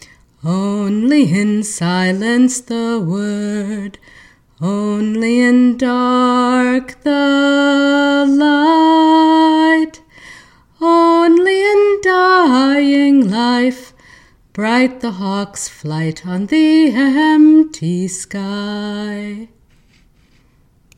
Forgive my wobbly voice.